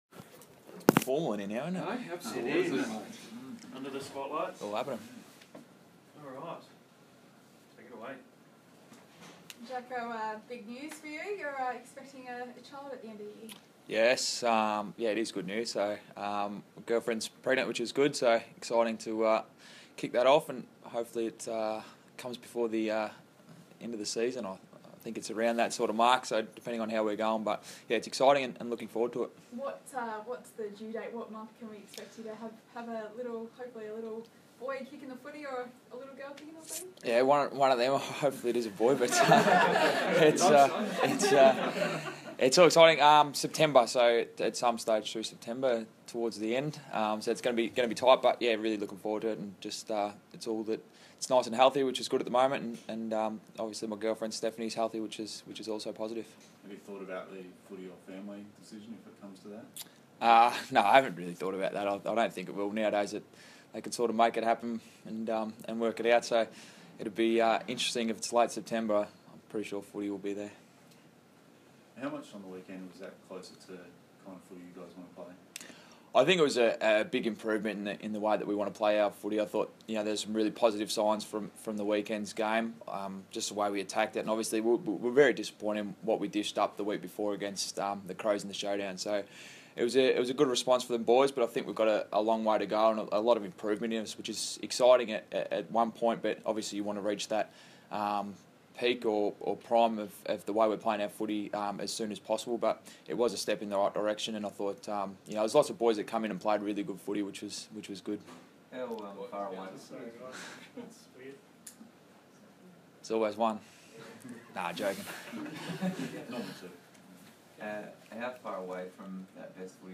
Jackson Trengove press conference - Monday 11 April 2016
Jackson Trengove speaks to media after Friday night's 61 point win over Essendon.